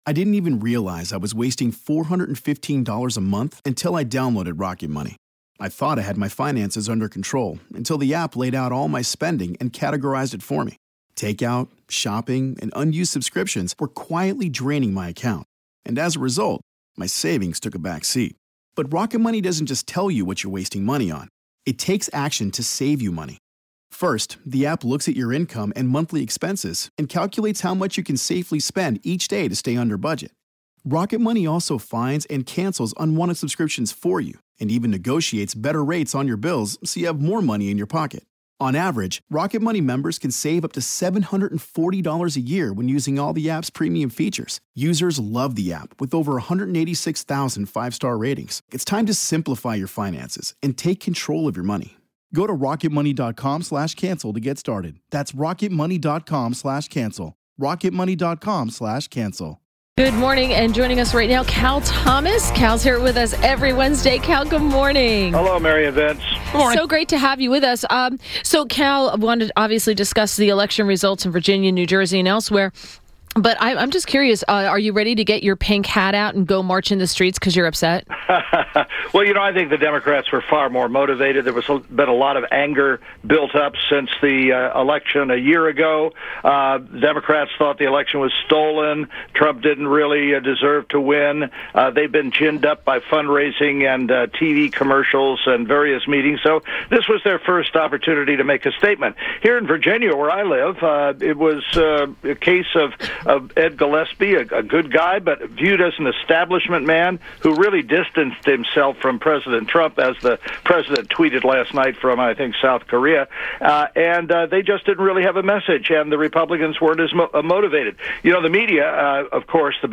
INTERVIEW – CAL THOMAS – Syndicated columnist – discussed results of elections in Virginia, New Jersey and elsewhere.